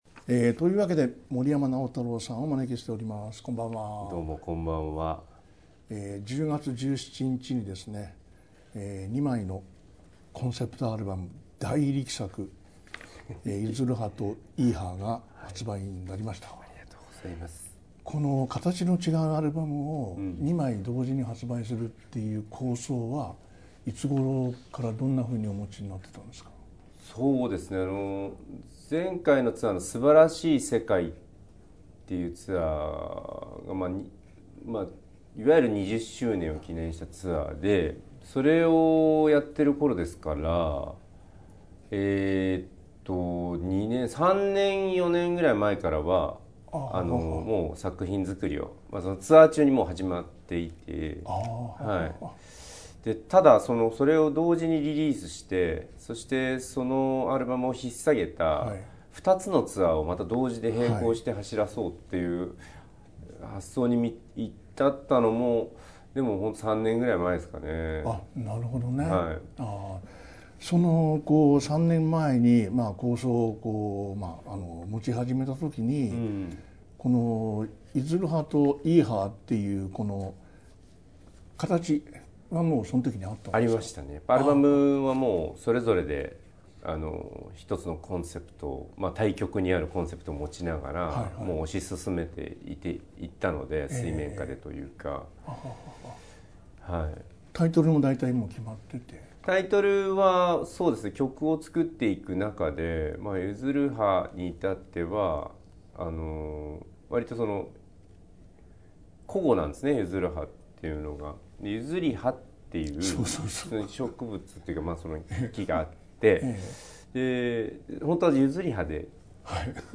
音楽評論家･田家秀樹が聞き出すアーティストの本音のインタビュー、J-POP界の裏話などJ-POPファンなら聞き逃せない魅力満載でお送りする30分。Podcastでは番組で放送した内容を「ほぼノーカット」でお送りしています。